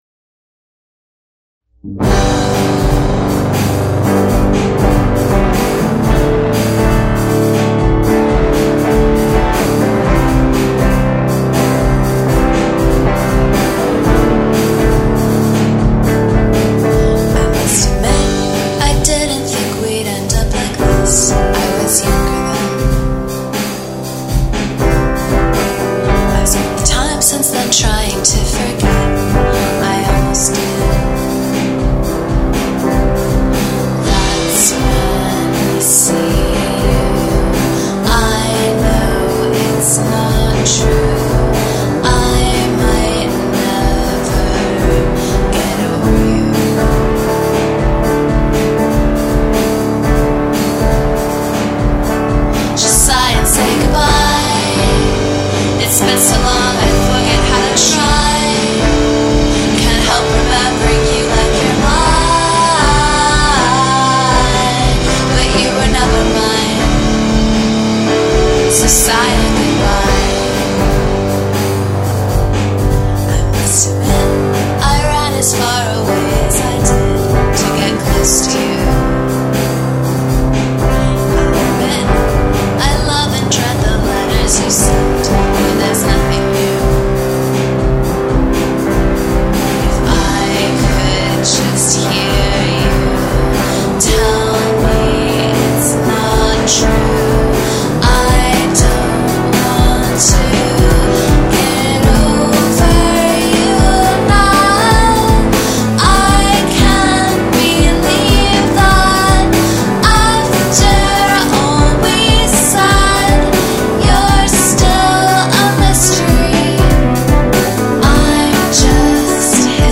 a-b-c-a-b-c->d-a-a
key of g (i think?)